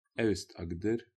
Aust-Agder (Norwegian: [ˈæ̂ʉstˌɑɡdər]